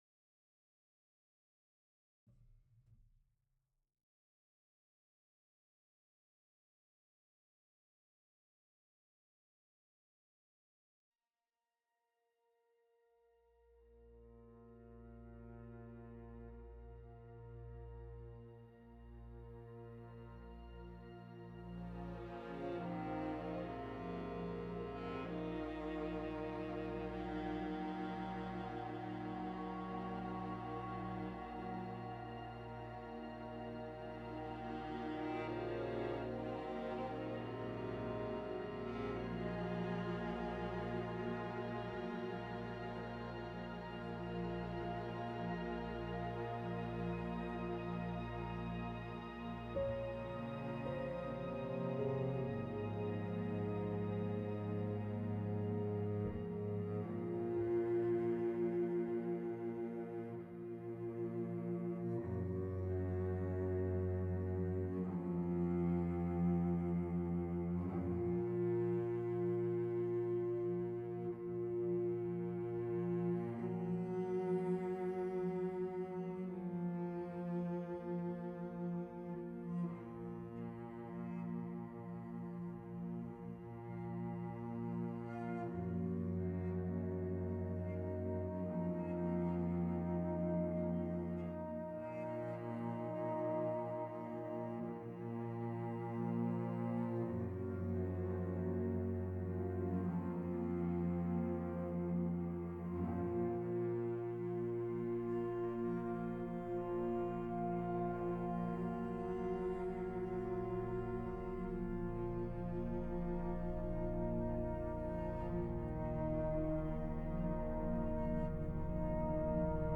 the score for the documentary